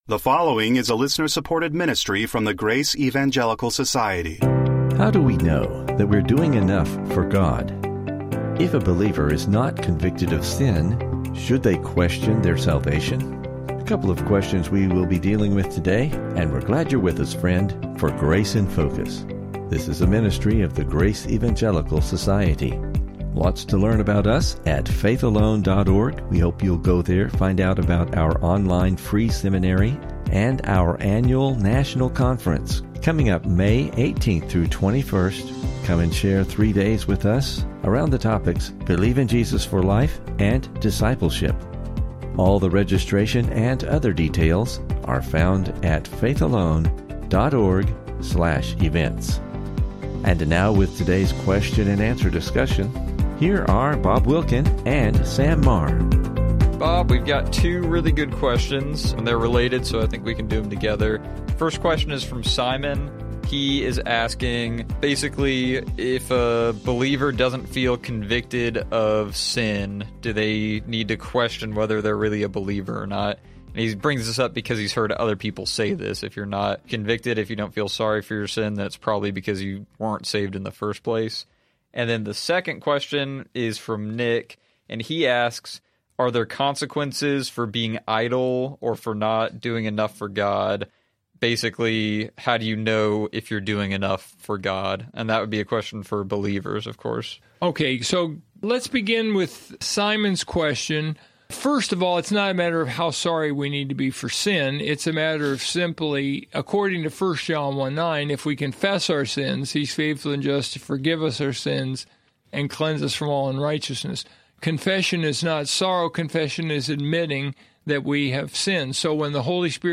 A couple of good questions and a great discussion on this episode.